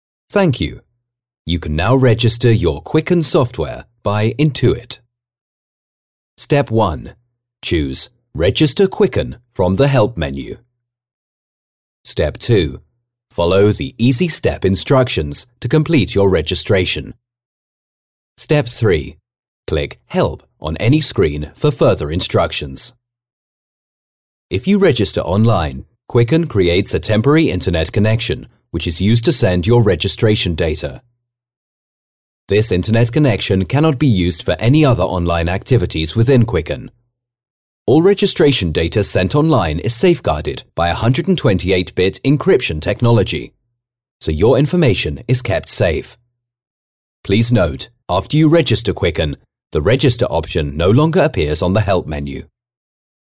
Meine Stimme kann warm, beruhigend, bestimmt oder energetisch wirken, und ich spreche ein klassisches, akzentfreies britisch Englisch.
Ein erfahrener englischer Sprecher mit einer warmen, dynamischen Stimme!
Sprechprobe: eLearning (Muttersprache):
An experienced VO talent, with a warm and dynamic voice.